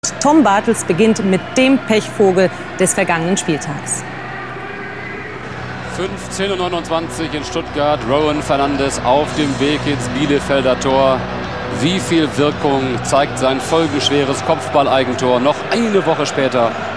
Dieses Lied such ich schon länger und habs in der aktuellen Sportschau im Hintergrund in nem kleinen Ausschnitt entdeckt:
Aber dort im Hintergrund ein Lied zu erkennen ist ansich schon schwer, dann auch noch den Titel zu erkennen ist hart.^^